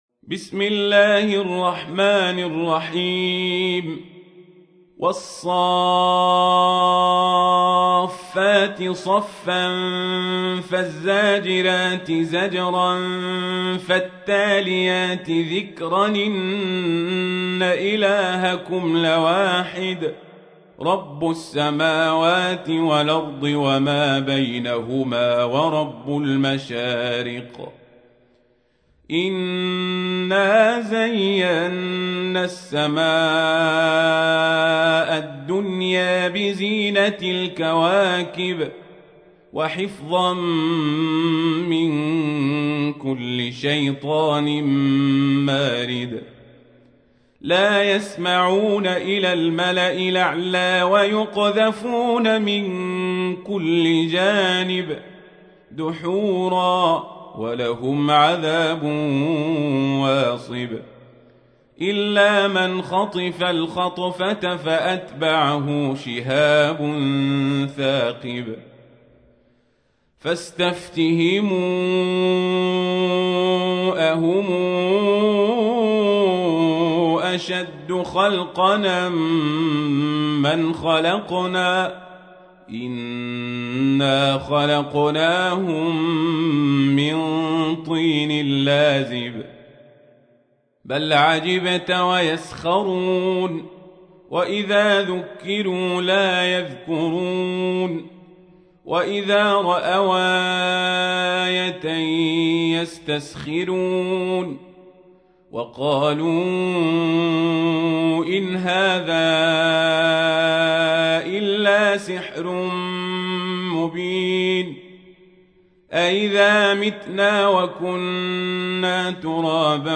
تحميل : 37. سورة الصافات / القارئ القزابري / القرآن الكريم / موقع يا حسين